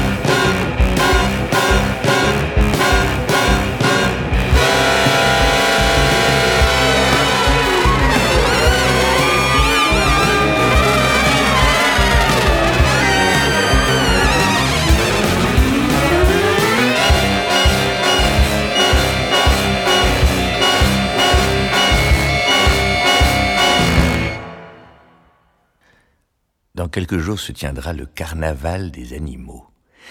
• Voix :